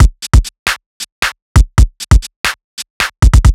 Tredwell Break 135.wav